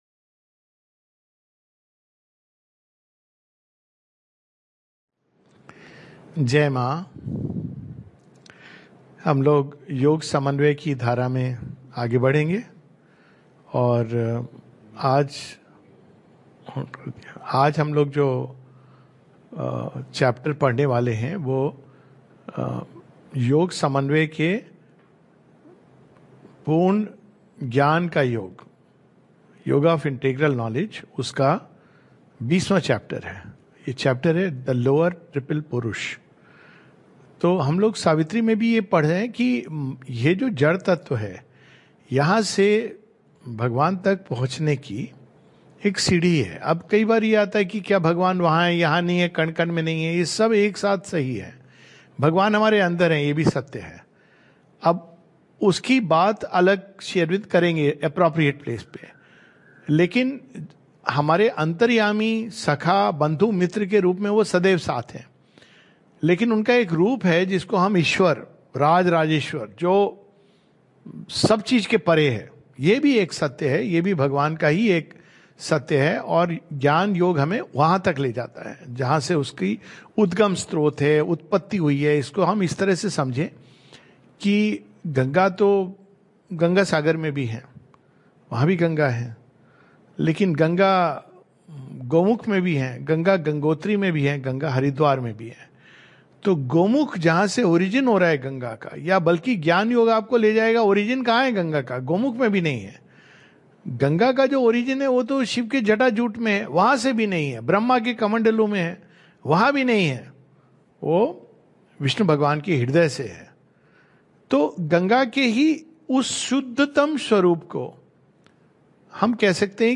[The Lower Triple Purusha] This talk is a summary of Chapter 20 of Part 2 of The Synthesis of Yoga.